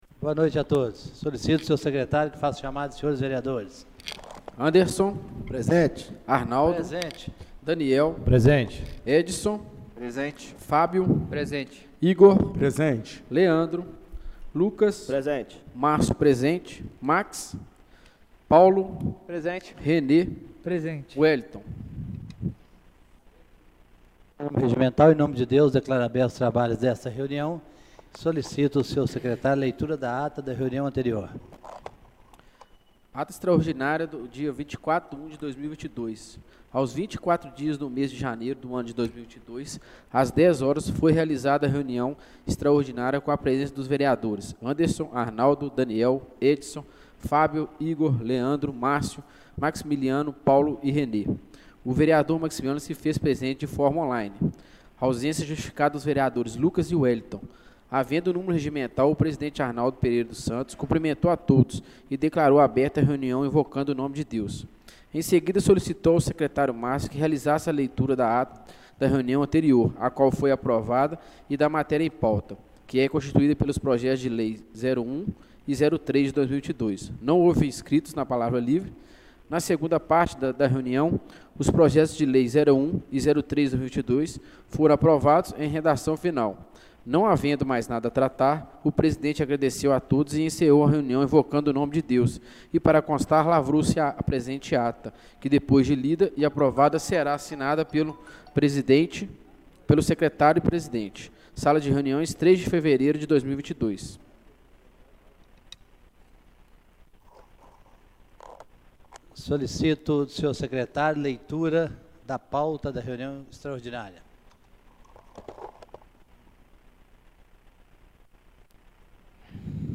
Reunião Extraordinária do dia 03/02/2022